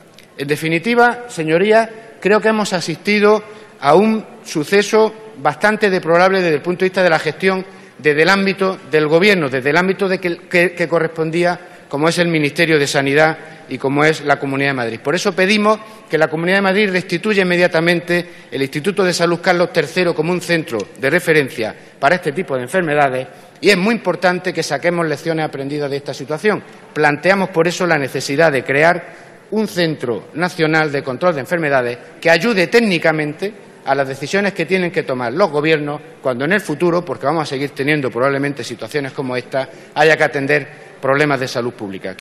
Fragmento de la intervención de José M. Olmos en el pleno del Congreso defendiendo a los profesionales de la sanidad y pidiendo el cese de Ana Mato 28/10/2014